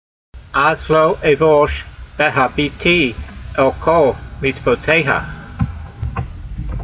v6_voice.mp3